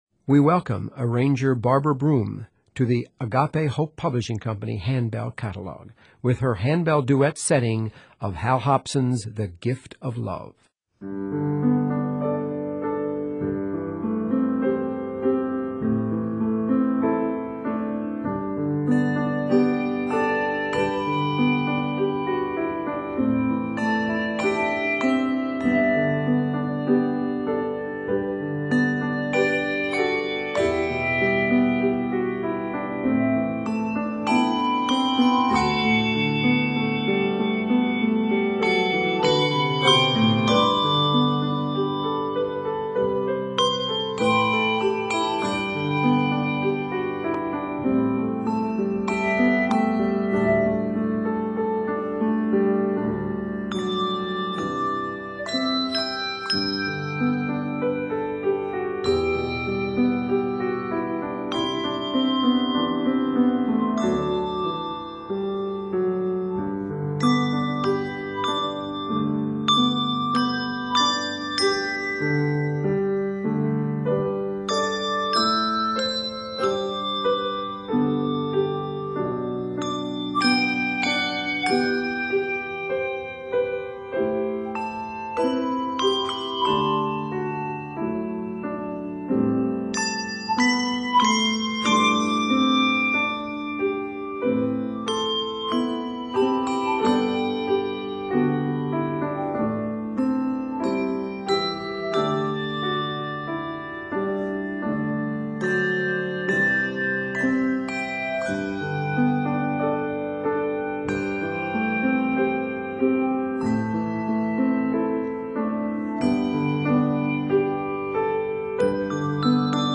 Set in G Major, measures total 65.
Duet
Hymn Tune